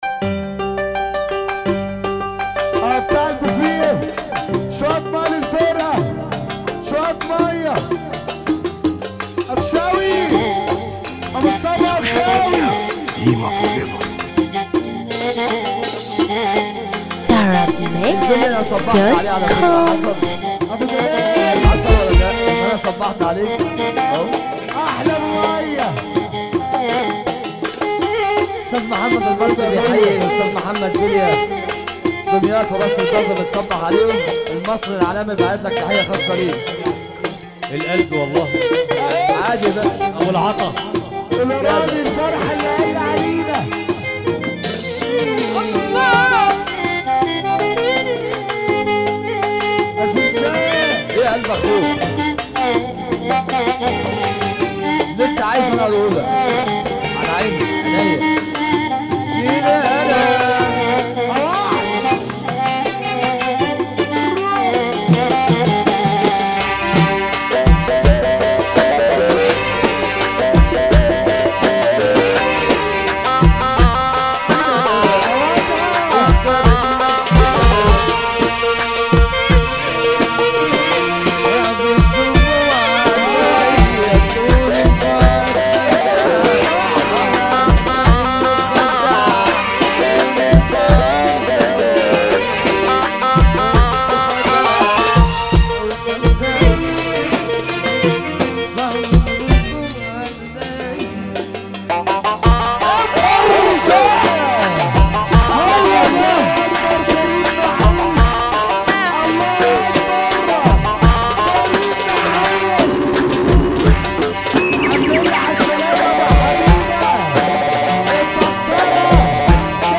موال
حزينة موت